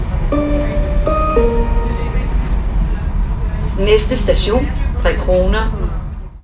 Højttalerudkald - "Næste station.."